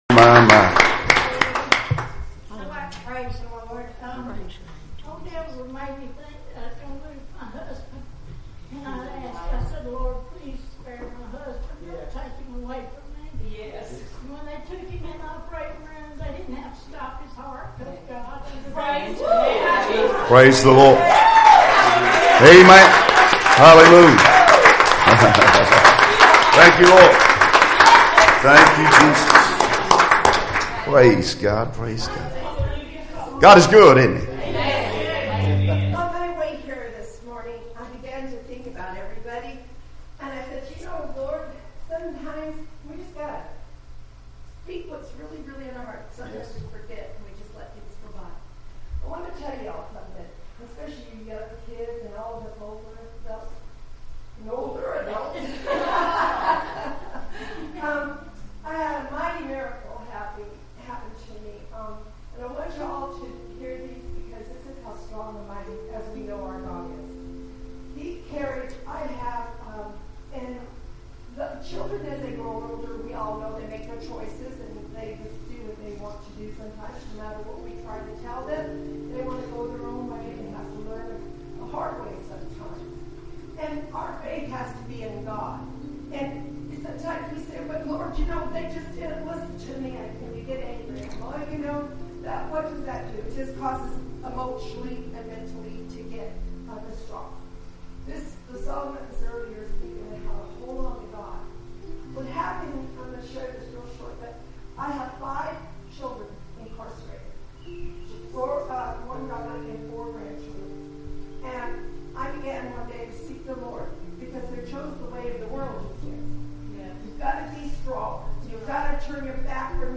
Passage: 1 kings 17:9-16 Service Type: Sunday Morning Services Topics